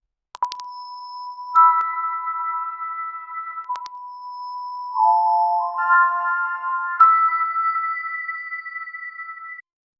The transmission remains open, but only the dead silence can be heard. 0:10 Don't move, you're being detained. 0:15 A slow strings, what is creepy, making atmoshpere harder. 0:20 Paranoid music starts to rise. 0:10
paranoid-music-starts-to--5zfo4gtr.wav